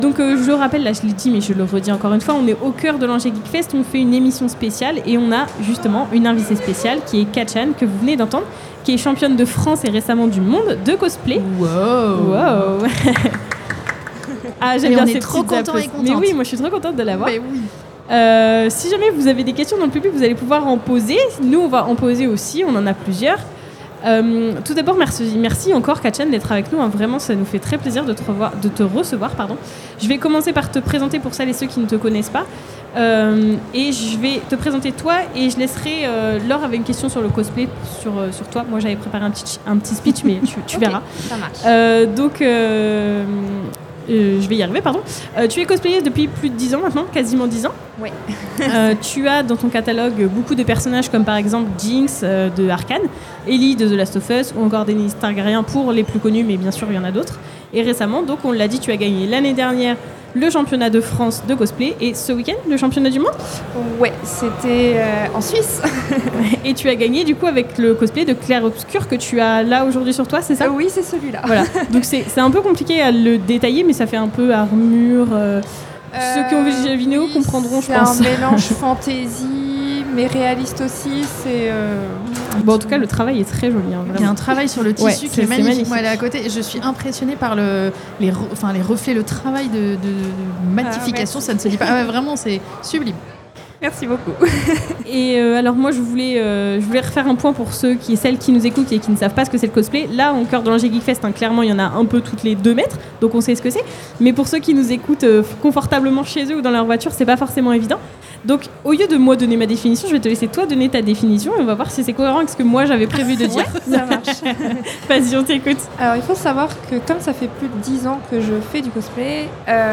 Interview exclusive
En plein coeur de la 7ème édition de l'Angers Geekfest, l'équipe de Clap'N'Chat est réuni pour accueillir dans l'émission la cosplayeuse multi récompensé